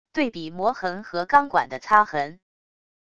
对比磨痕和钢管的擦痕wav音频